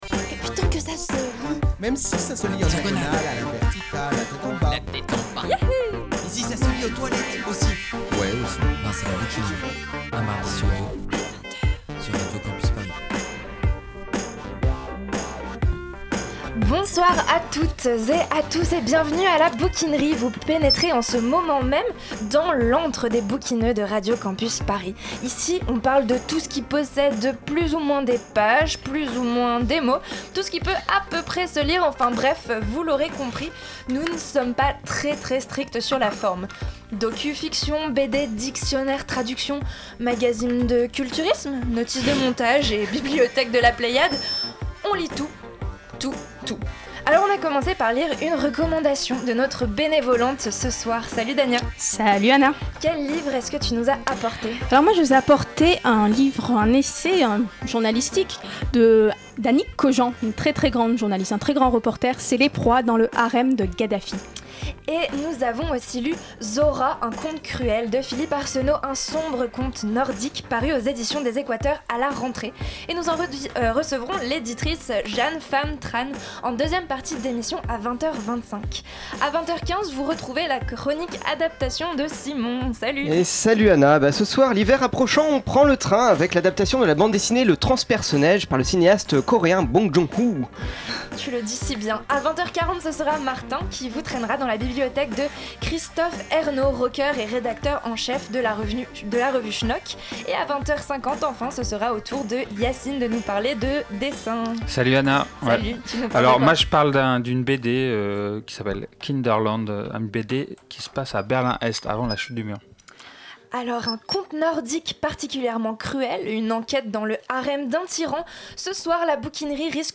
Partager Type Entretien Culture mardi 16 décembre 2014 Lire Pause Télécharger Beaucoup de nanas à la bouquinerie ce soir !